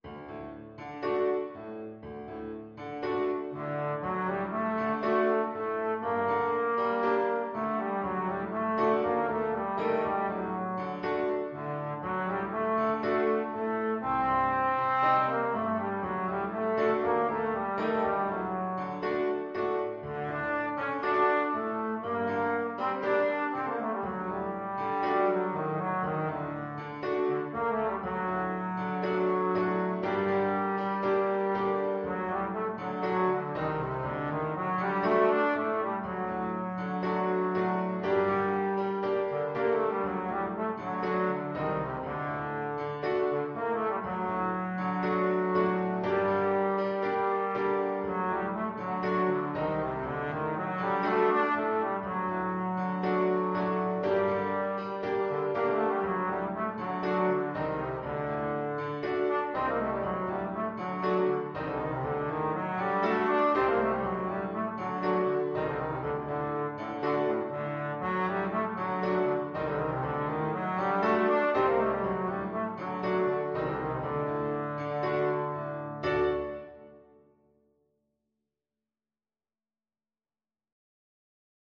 Traditional Trad. Araber Tanz (Klezmer) Trombone version
Trombone
A3-D5
G minor (Sounding Pitch) (View more G minor Music for Trombone )
4/4 (View more 4/4 Music)
Allegro moderato =120 (View more music marked Allegro)
Traditional (View more Traditional Trombone Music)
world (View more world Trombone Music)